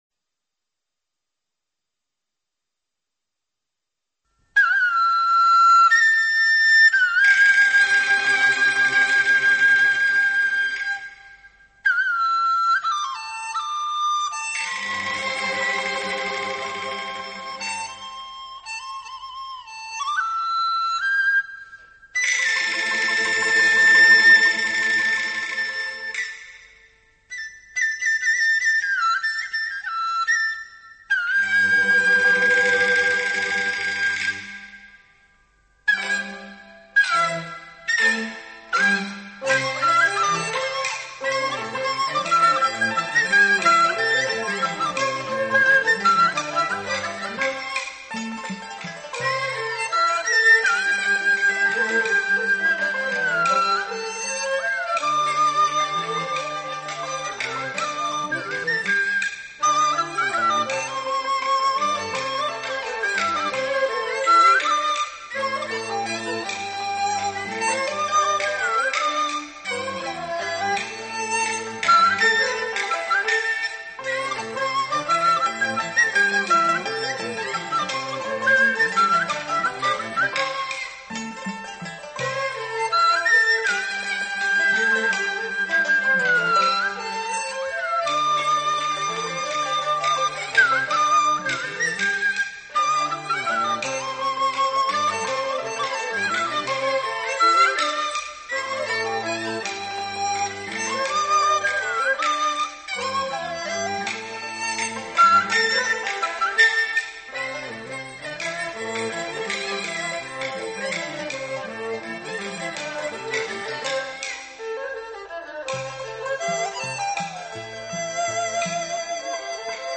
笛子，又称竹笛，是中国最古老的乐器之一，古代称为“篴”。